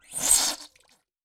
Blood_Squirt_3.wav